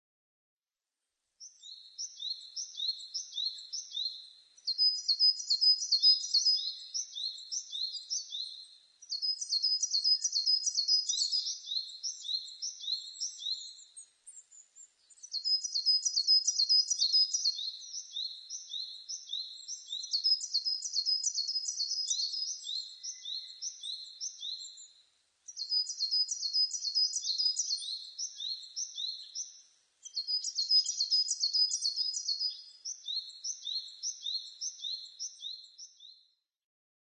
ヒガラ　Parus aterシジュウカラ科
日光市稲荷川中流　alt=730m  HiFi --------------
Mic.: Sound Professionals SP-TFB-2  Binaural Souce
他の自然音：　 シジュウカラ・ウグイス